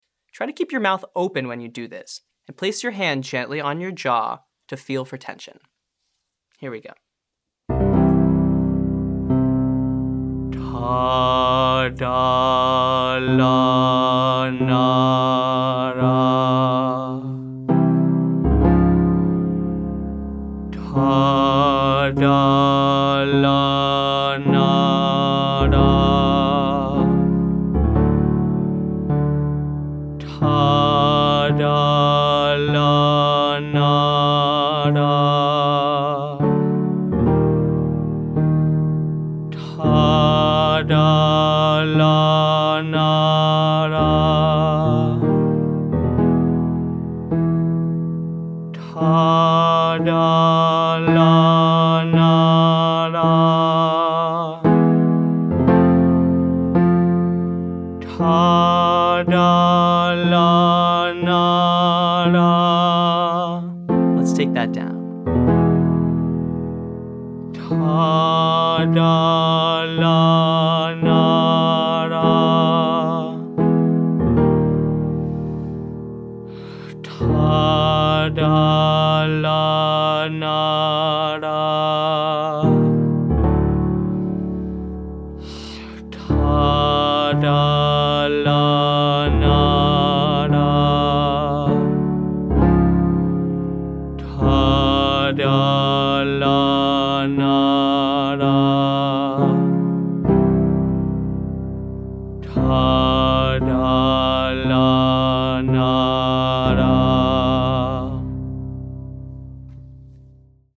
• Ta, Dah, Lah, Nah, Rah